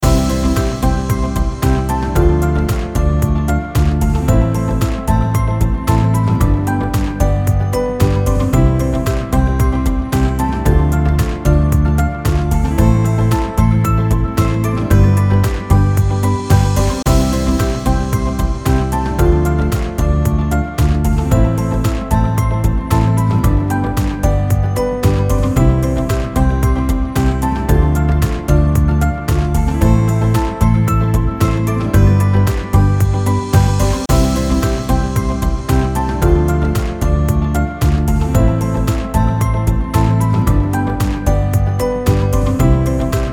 incomingcall.mp3